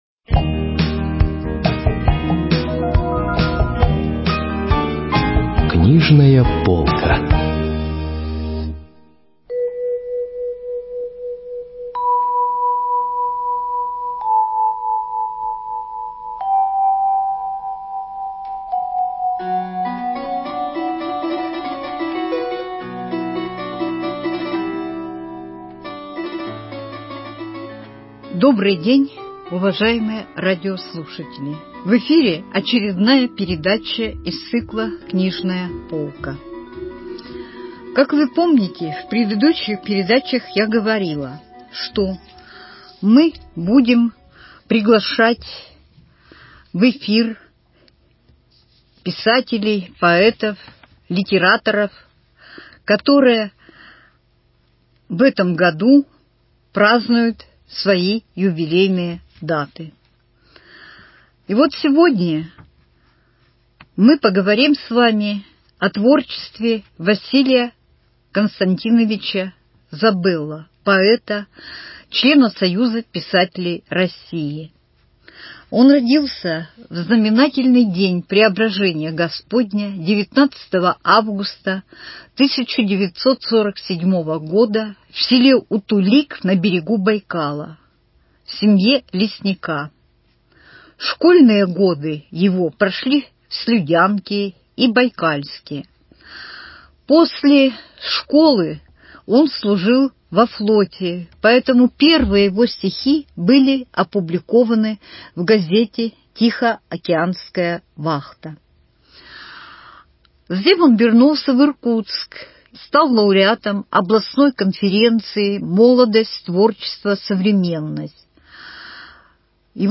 беседует с поэтом